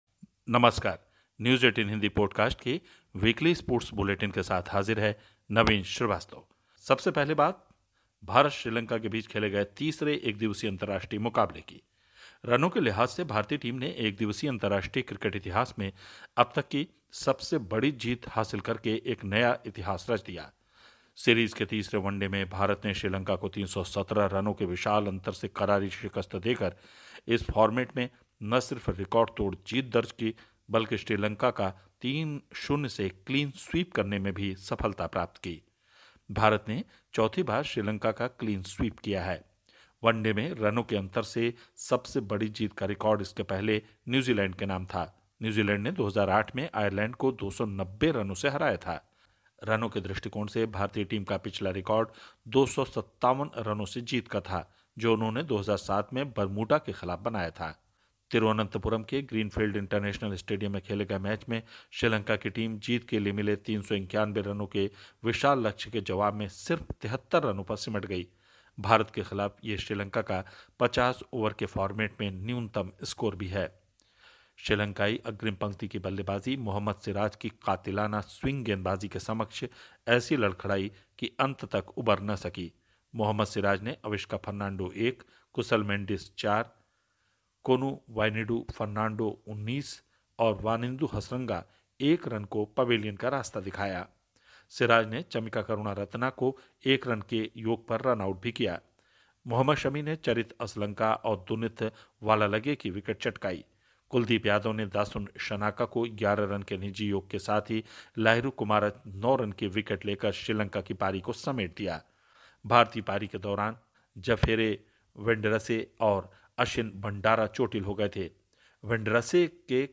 स्पोर्ट्स बुलेटिन / Podcast: भारत का हर ओर दबदबा, क्रिकेट में क्लीन स्वीप, हॉकी वर्ल्ड कप में धमाकेदार आगाज़